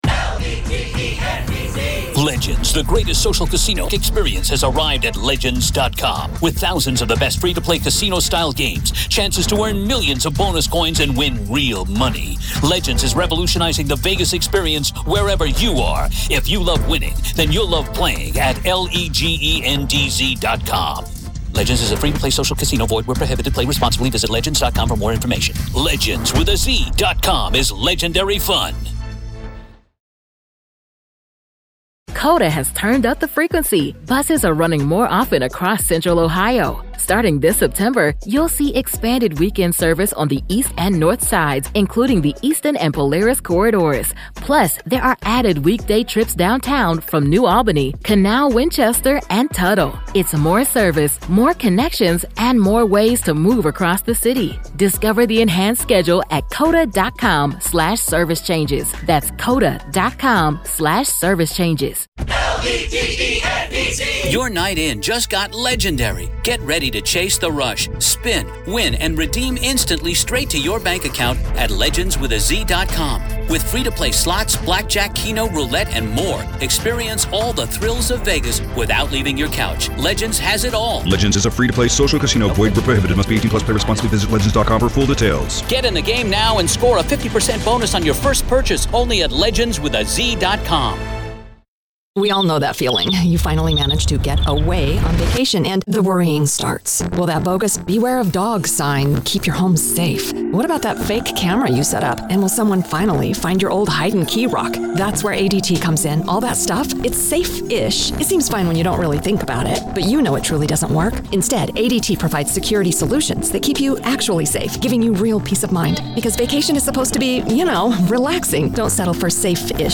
Telles Takes the Stand-Raw Court Audio-NEVADA v. Robert Telles DAY 6 Part 3